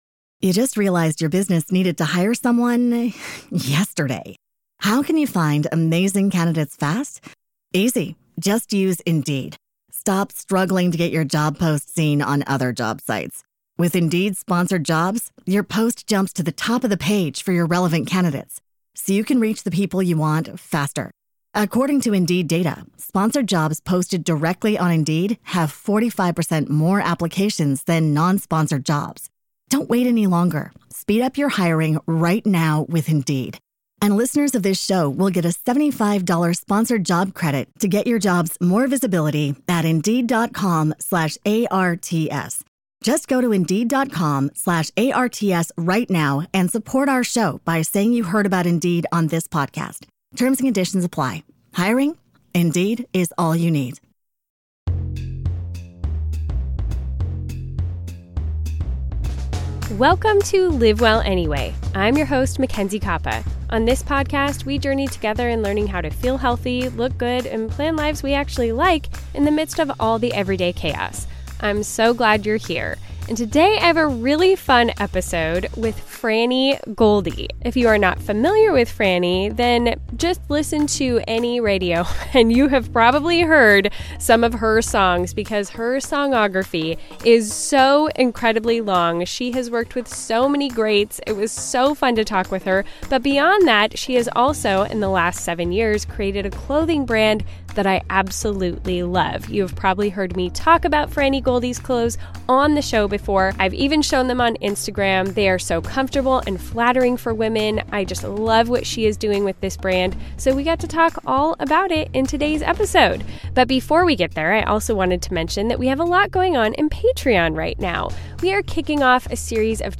It’s a fun conversation with a heart for women who support each other in developing our dreams.